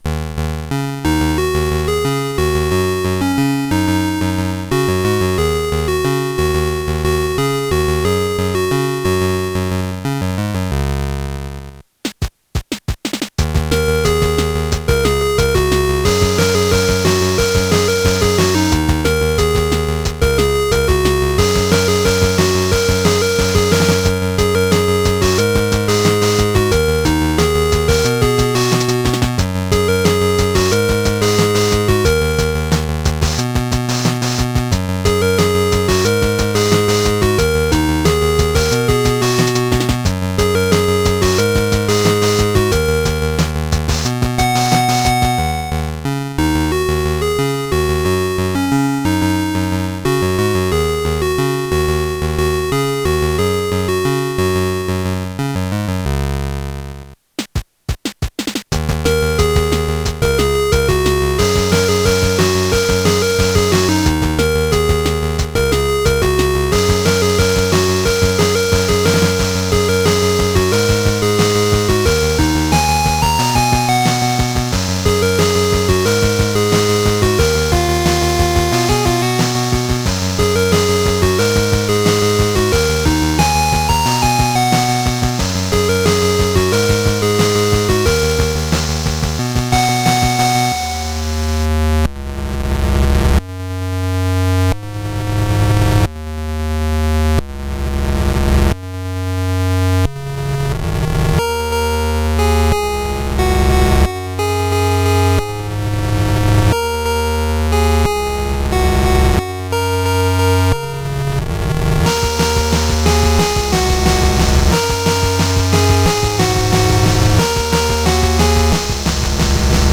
This is my first chiptune. LSDJ on DMG-01 without pro sound mod.